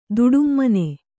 ♪ duḍummane